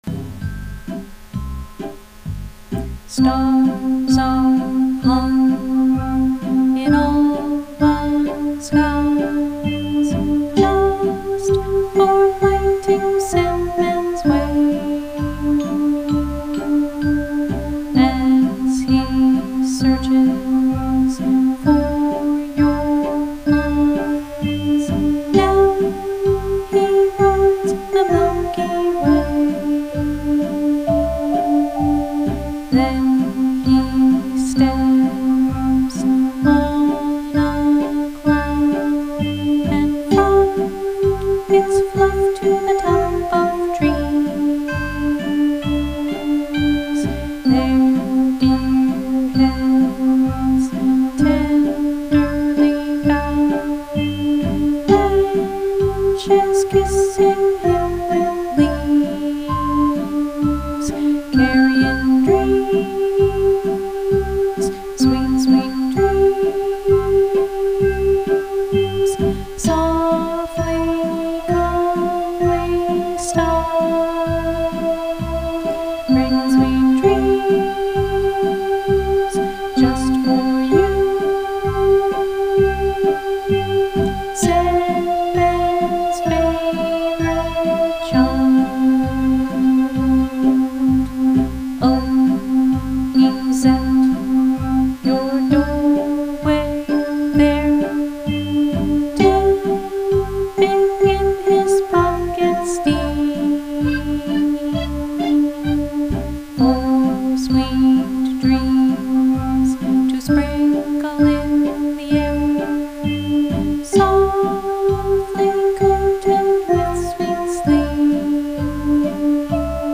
Lullaby
vocal